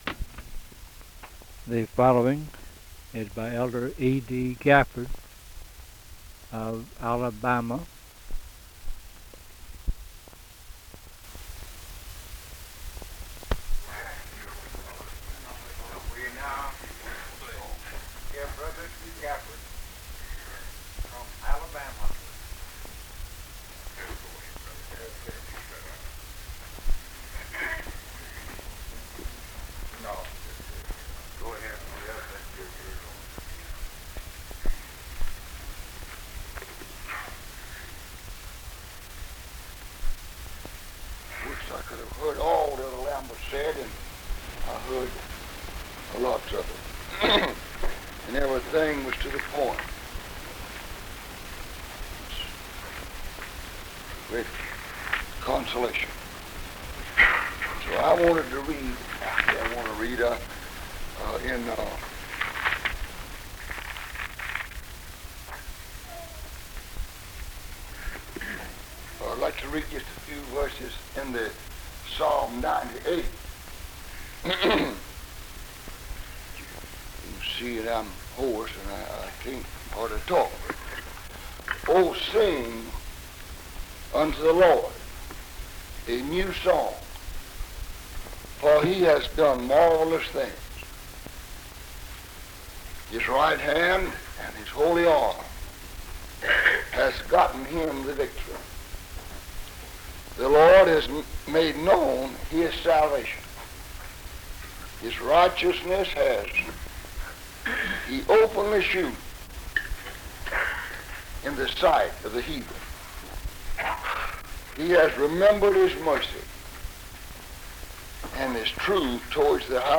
Primitive Baptists